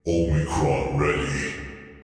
Subject description: Some very magnetic low pitched voices for my self made unit 'Omicron'!
I used professional dubbing tools and workflows, and cooperated with a stunning post-processing assistant.
Very Apocalypse-like these.